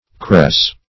Cress \Cress\ (kr[e^]s), n.; pl.